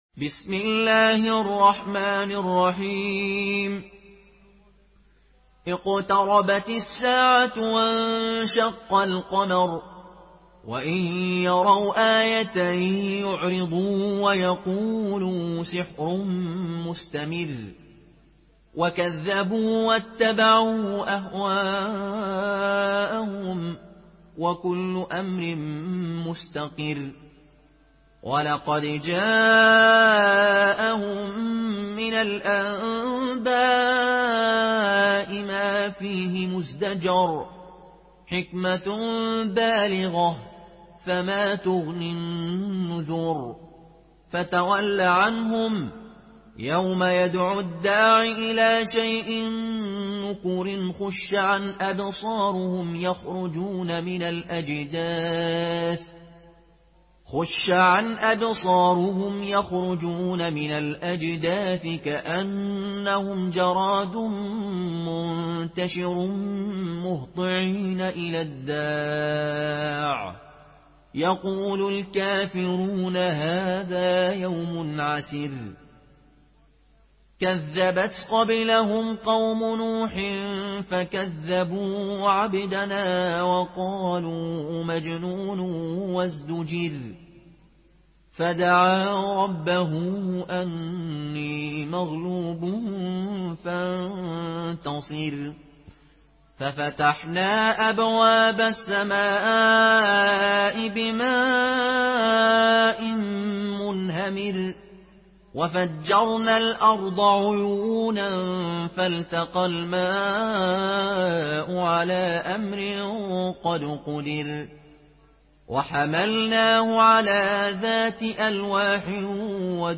Murattal Qaloon An Nafi